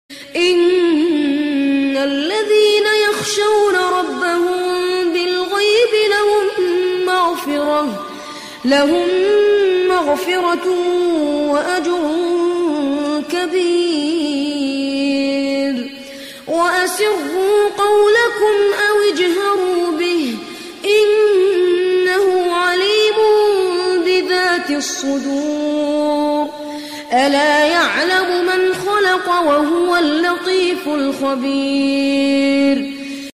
شنو اسم هذا القارى صوته كلش حلو